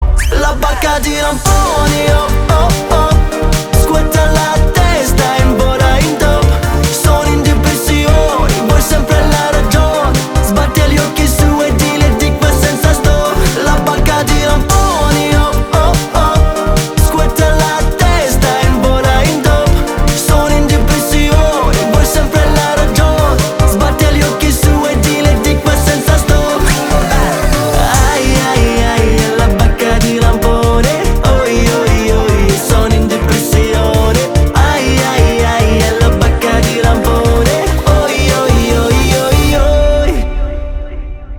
поп
итальянские